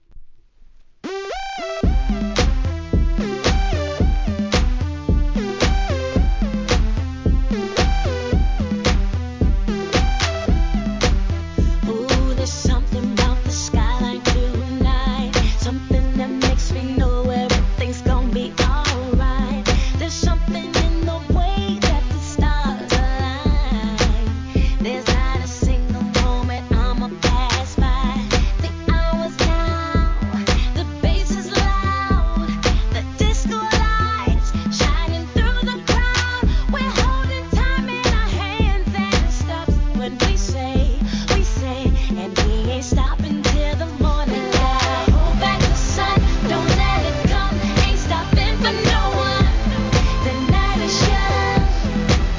HIP HOP/R&B
ノリのいいモコモコBEATにシンセのメロディーがフロア受けもバッチリな人気曲カット!!